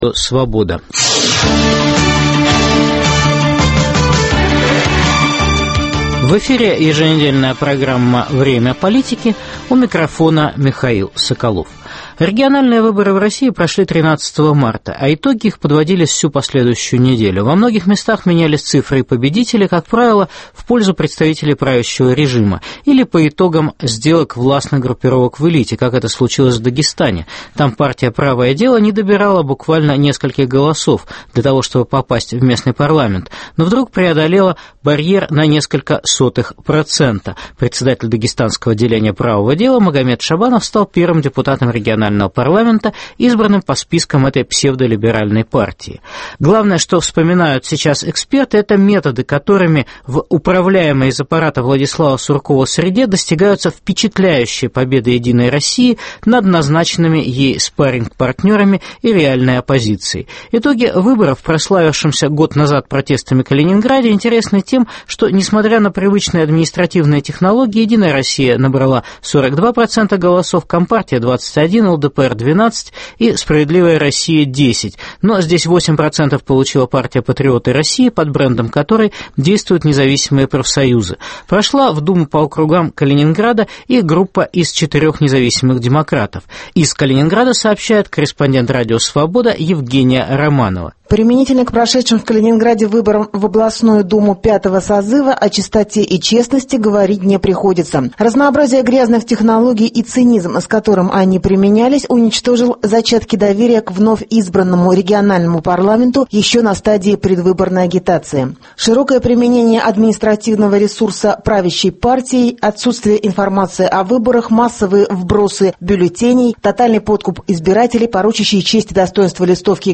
Стратегия "убедительной" победы "партии власти" : "чернуха", давление, пропаганда, подкуп, вброс, фальсификация... Репортажи корреспондентов "Радио Свобода" в Тверской, Калининградской, Курской, Орловской, Оренбургской областях и Республики Коми по итогам региональных выборов 13 марта.